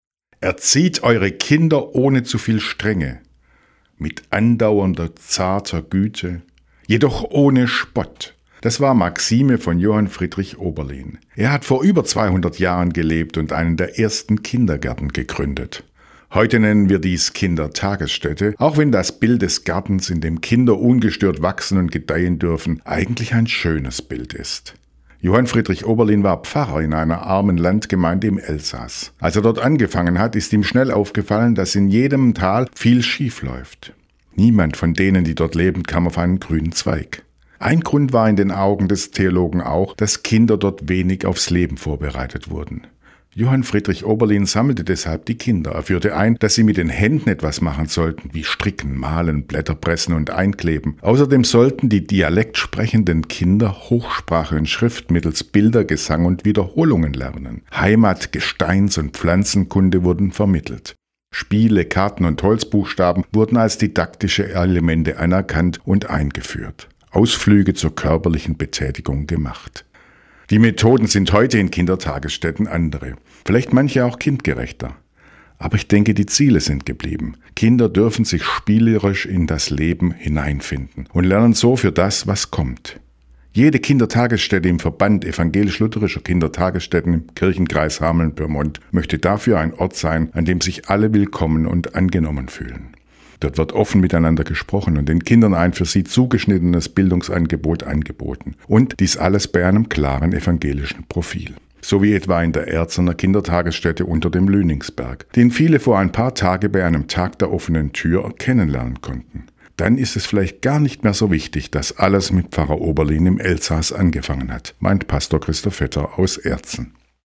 Radioandacht vom 26. September